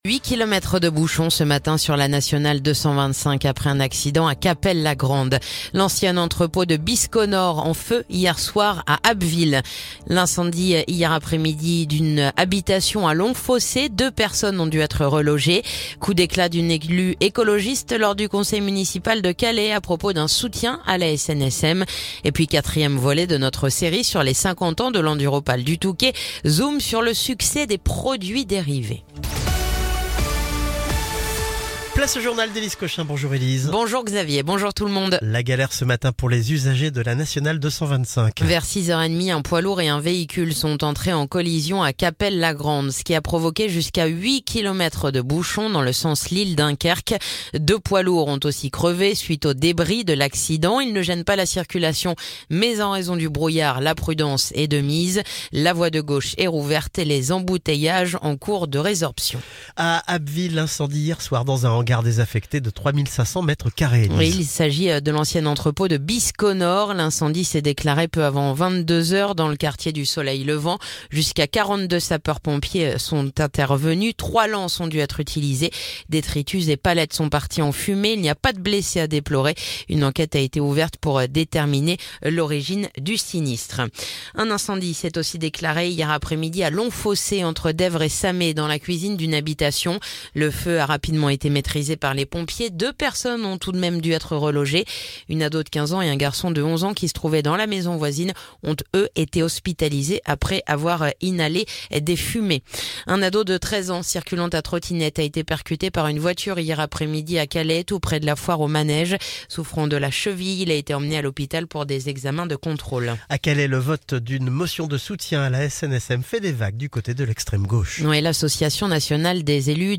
Le journal du jeudi 6 février